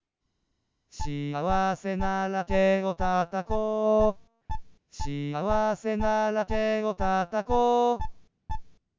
Singing Voice Synthesis
Below are some sample wav files of singing voice synthesized WITH and WITHOUT time-lag models: